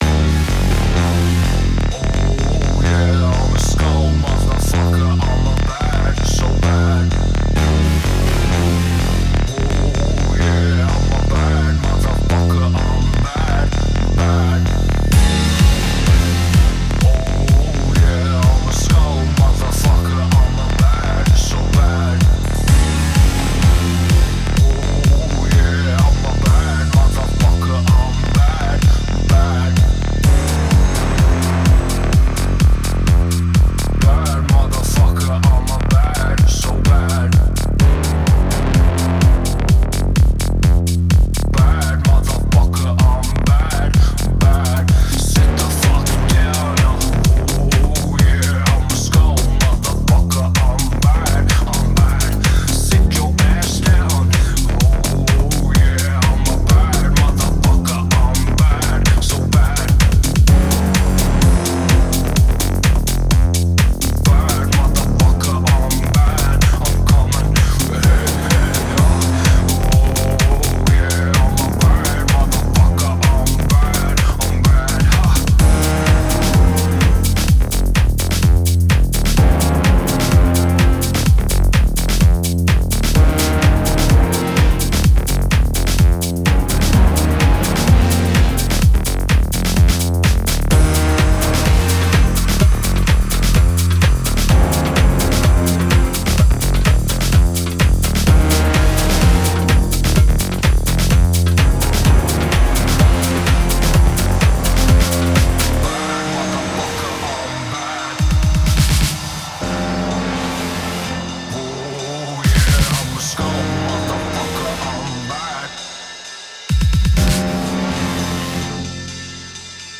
This filthy groove